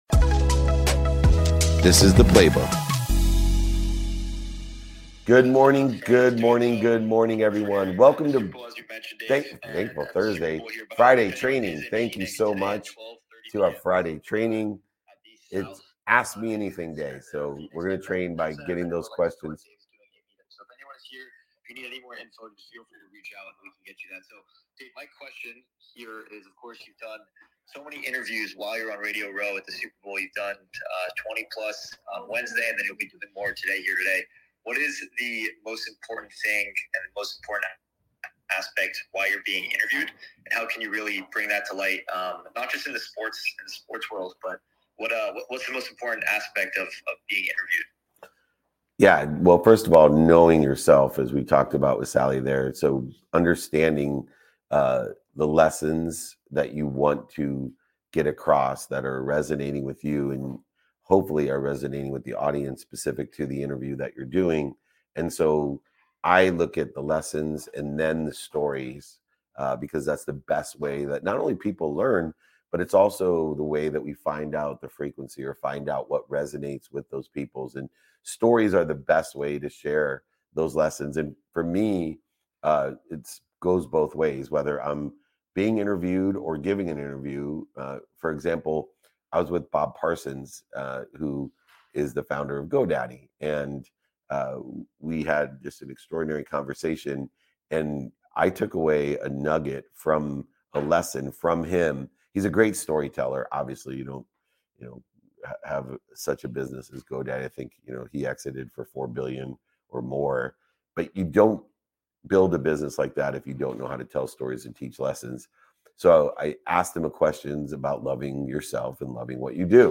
Today's episode is a recap of our Live Q&A on Friday.
Each weekly training provides a new topic with a correlated training guide to provide you with pragmatic strategies to implement in your daily activity to achieve your goals. They take place live every Friday at 7 am PST/10 am EST via a webinar with LIVE Q&A submitted by attending guests.